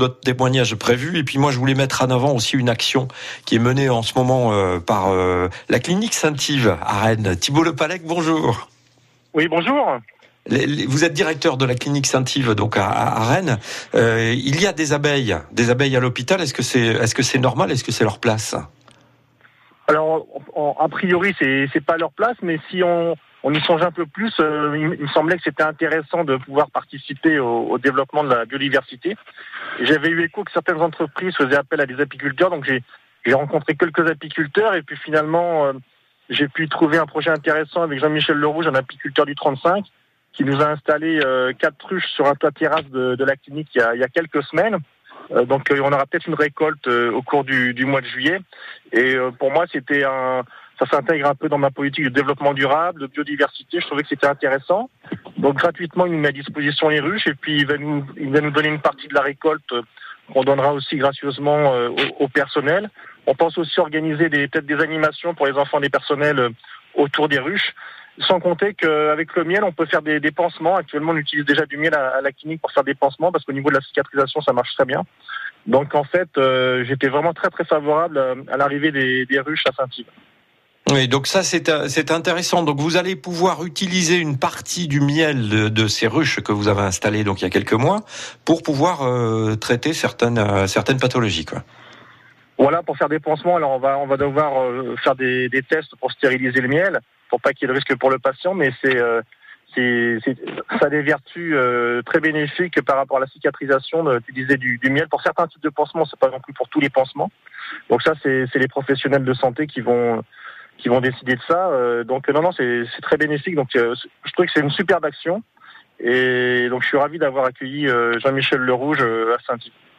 Il est intervenu dans l’émission « La vie en bleu » diffusée sur France Bleu Armorique.